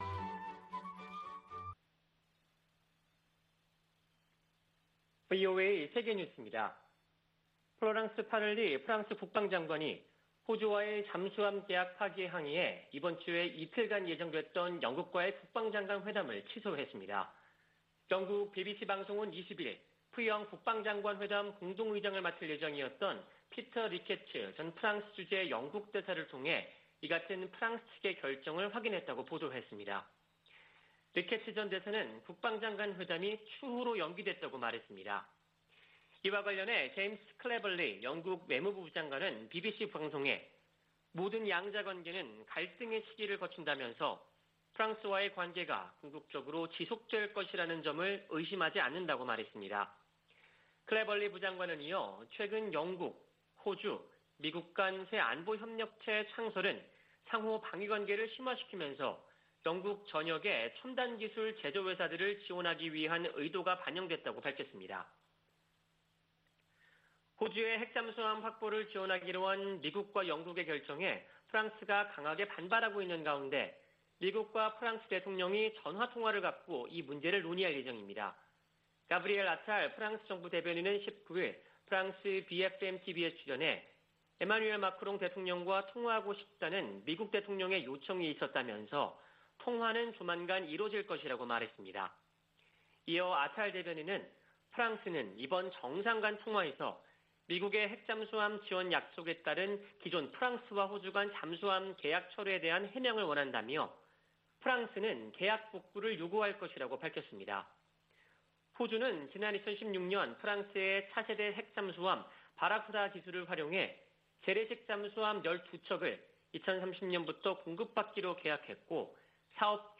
VOA 한국어 아침 뉴스 프로그램 '워싱턴 뉴스 광장' 2021년 9월 21일 방송입니다. 유엔주재 미국 대사가 북한의 최근 미사일 발사 관련 사안을 대북제재위원회에서도 논의할 것이라고 밝혔습니다. 존 하이튼 미 합참의장은 북한이 미사일 역량을 빠른 속도로 발전시켰다고 17일 말했습니다. 열차를 이용한 북한의 탄도미사일 시험발사는 선제적 대응을 어렵게할 것이라고 미국의 군사 전문가들이 분석했습니다.